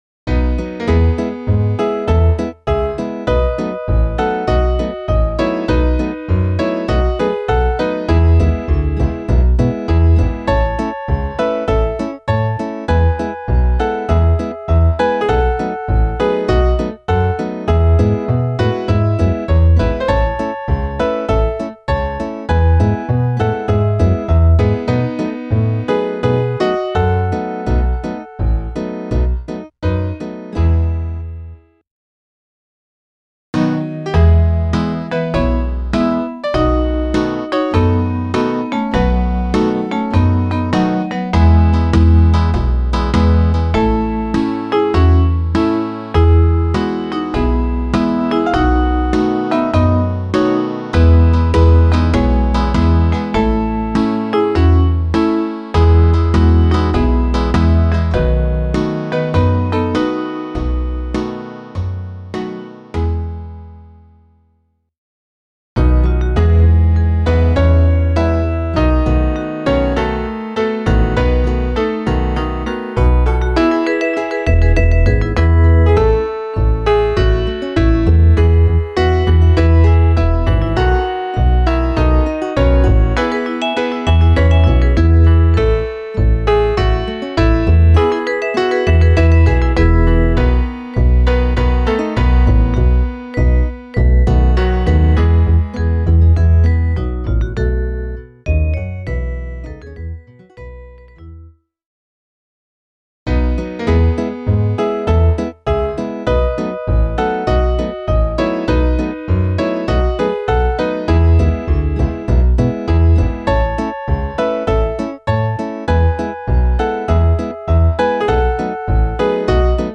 Variationen